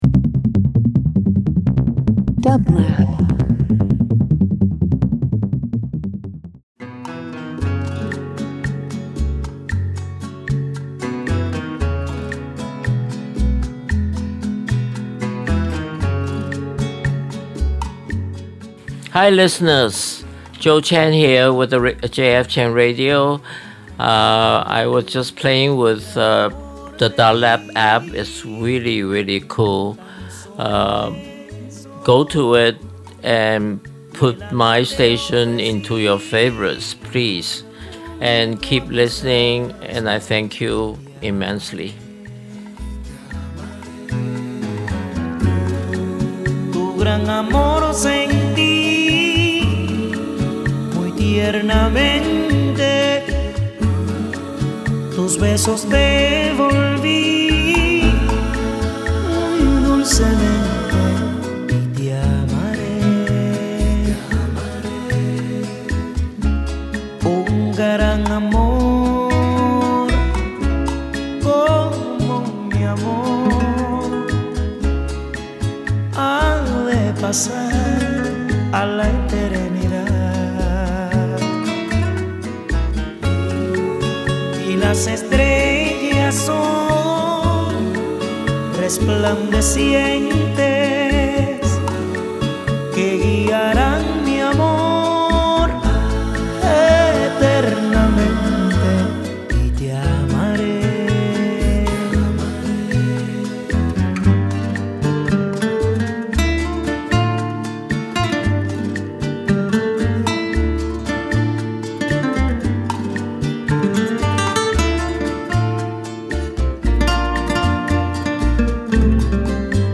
Brazilian Dance Electronic Jazz Latin